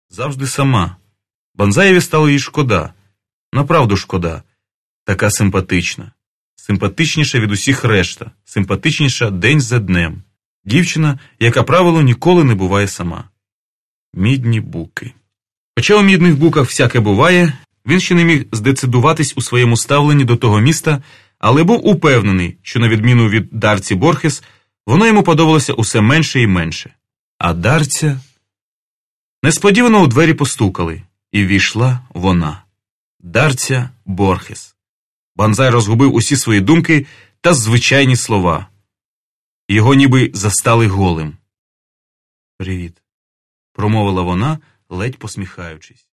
Каталог -> Аудіо книги -> Модерна